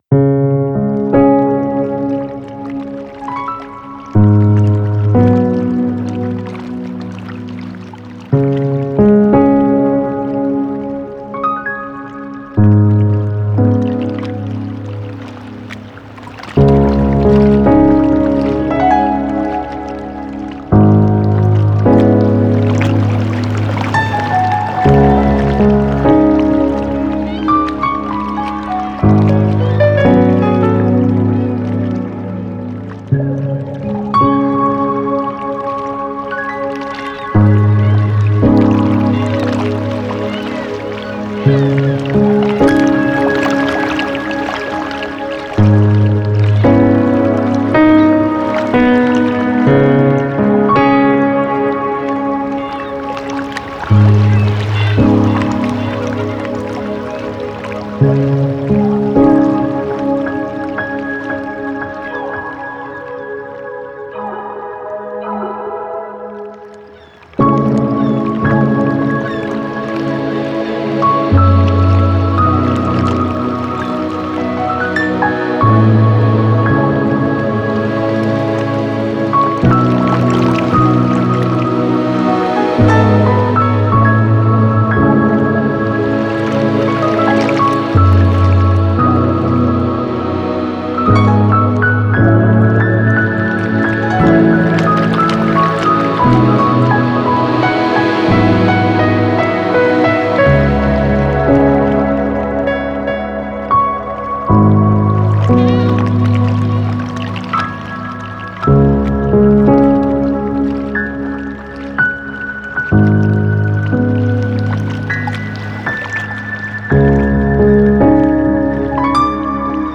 Genre: Relax, Meditation, Ambient, New Age, Ambient.